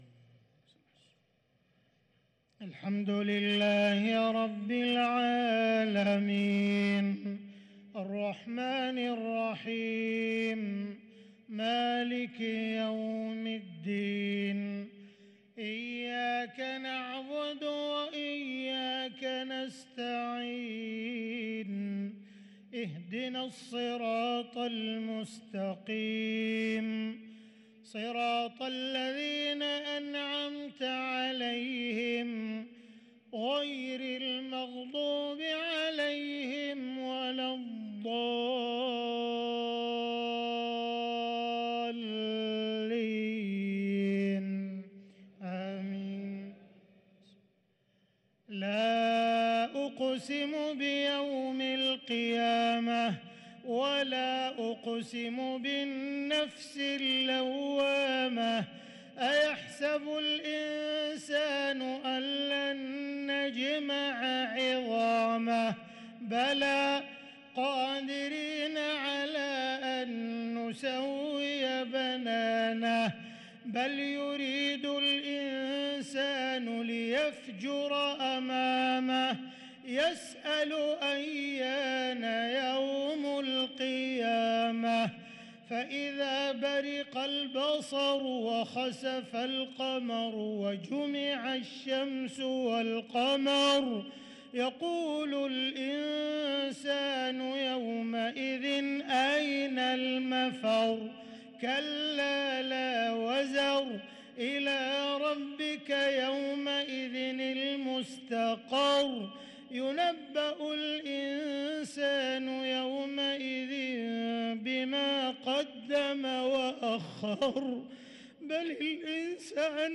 صلاة العشاء للقارئ عبدالرحمن السديس 28 ربيع الأول 1444 هـ